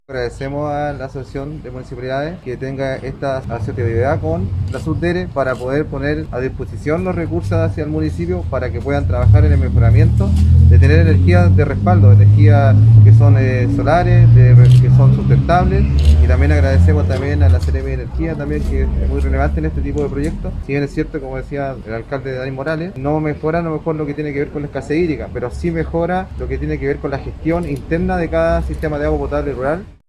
La presentación de la iniciativa se efectuó en la sede del Comité de Agua Potable Rural de La Palma, en Quillota y fue encabezada por el presidente de la Asociación Regional de Municipalidades y alcalde de Limache, Daniel Morales Espíndola.